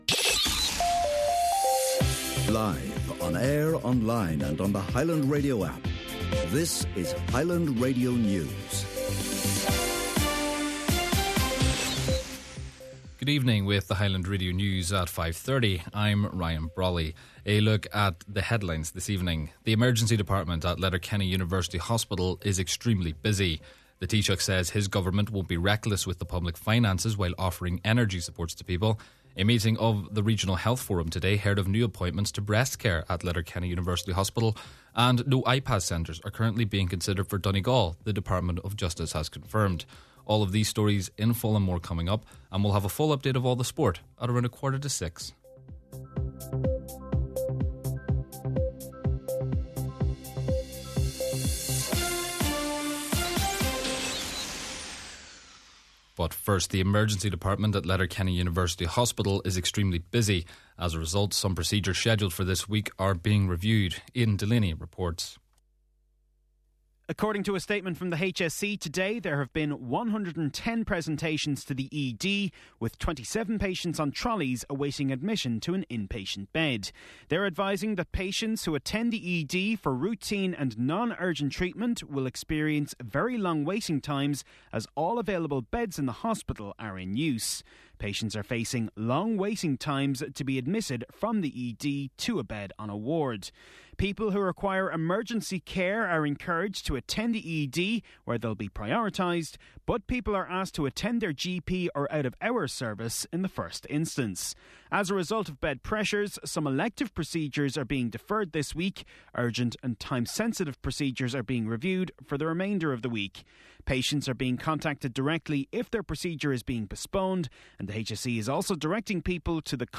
Main Evening News, Sport and Obituary Notices – Tuesday March 24th